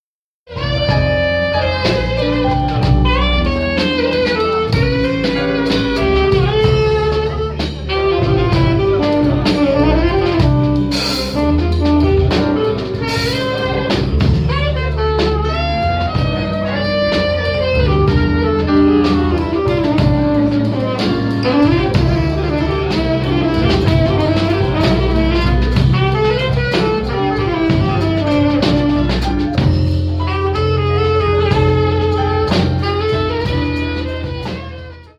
Soirée Jam EJCM & Musiù
Soirée jam / Jazz impro 22 mars 2019, à 20h30 Entrée libre – Chapeau Encore une soirée à ne pas manquer à Musiù !
Pour ceux qui ignorent le principe de la Jam, sachez que l’improvisation y règne en permanence.
La palette est vaste et dépend directement des gens présents, elle peut s’étendre de morceaux tirés du répertoire jazz standard à des improvisations totalement libres.
2017_jam_extrait.mp3